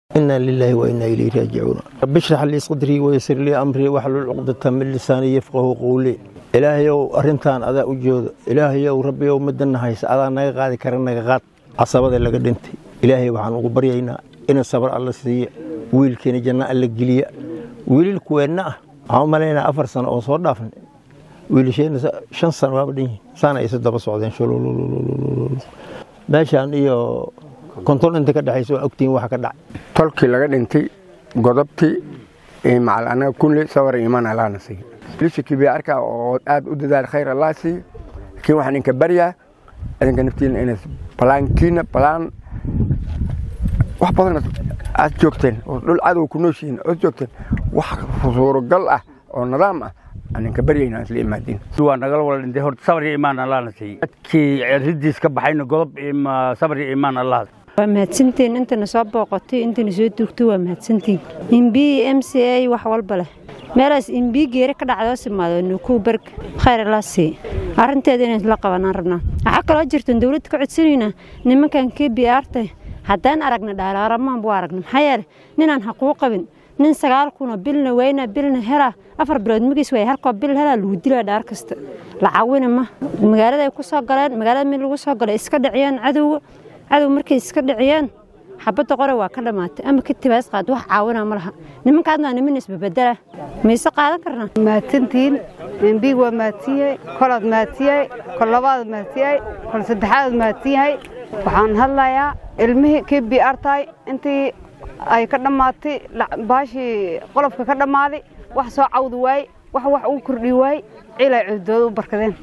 Qaar ka mid ah odayaasha iyo dadka deegaanka Tarbaj ayaa ka hadlay dilka.
Dhanka kale, xildhibaanka deegaanka Tarbaj Xussen Cabdi Barre ayaa ka hadlay falka dilka ah wuxuuna sheegay in ay iska difaaci doonaan dhagarqabayaasha.